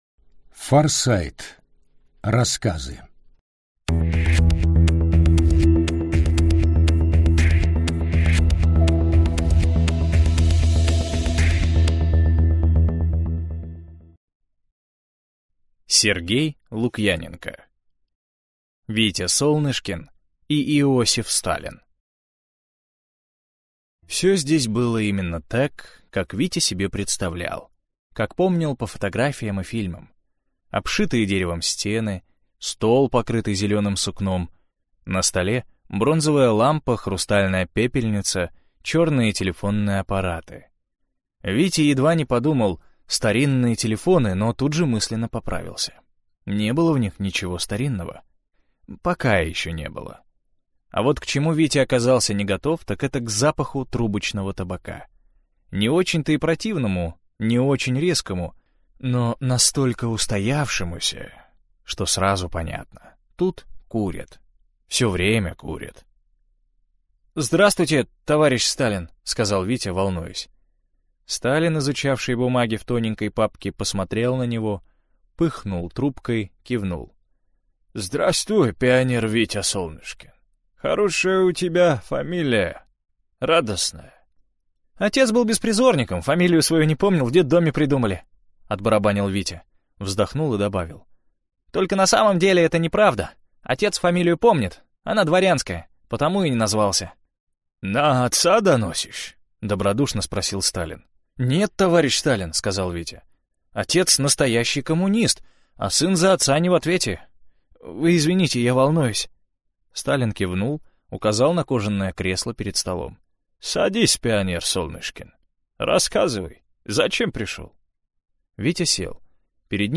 Аудиокнига Рассказы из книги «Форсайт» | Библиотека аудиокниг